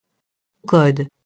code [kɔd]